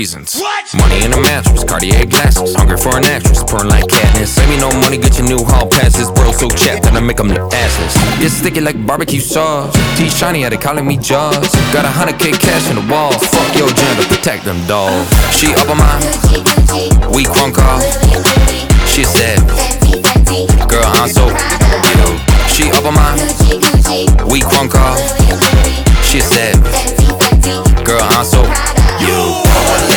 Жанр: Поп музыка / Танцевальные
Pop, Dance